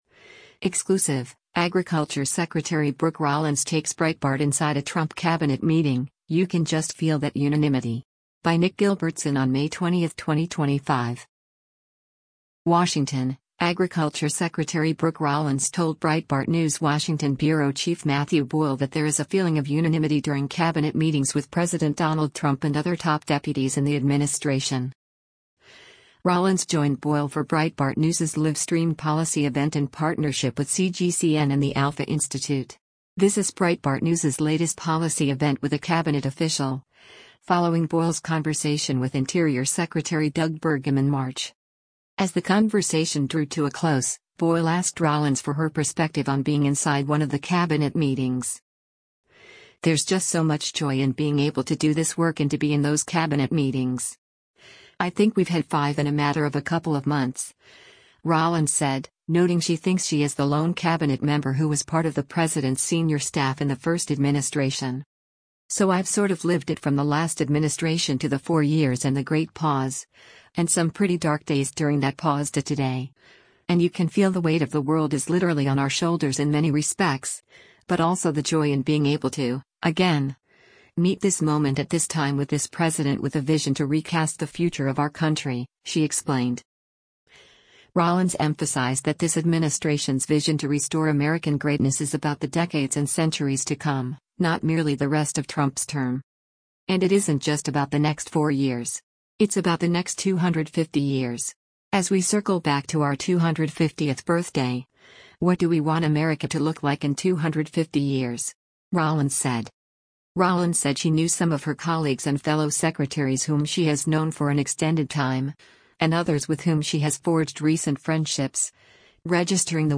live-streamed policy event